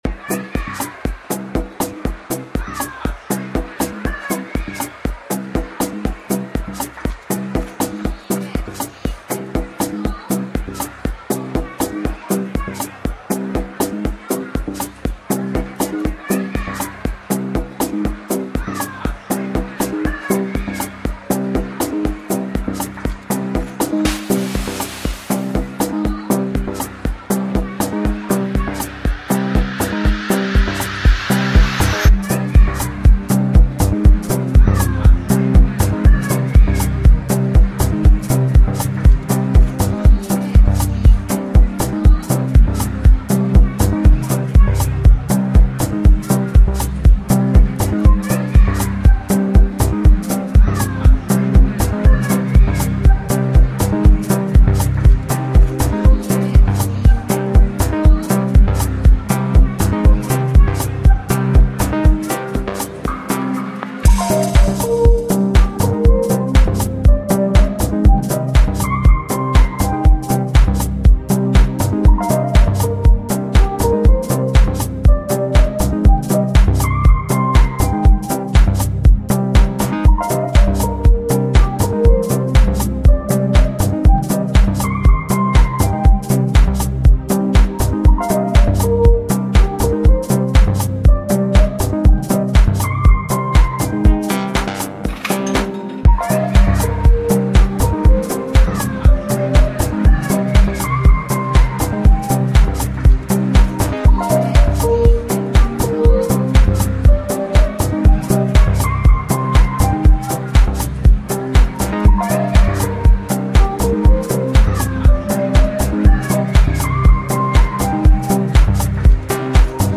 deep dubby but so danceable.
A great package for the lovers of the deep tech sound.